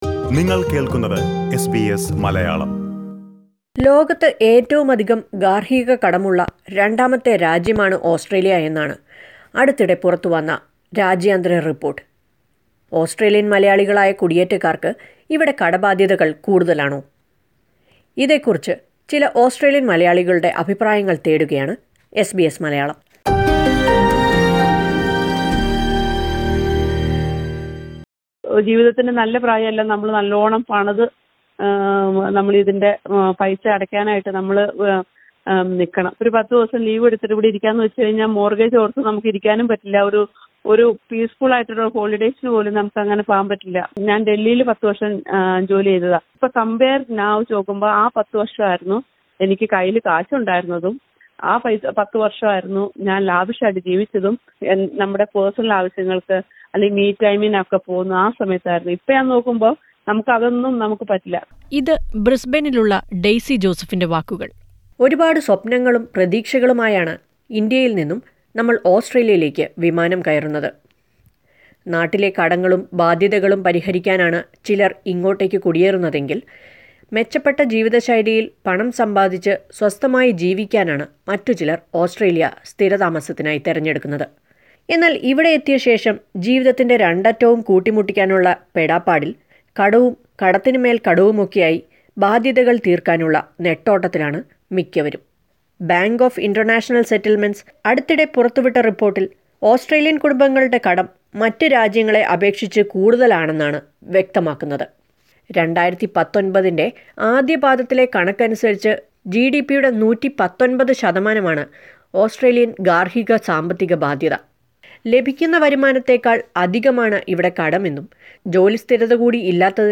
Listen to the opinions of a few Malayalees.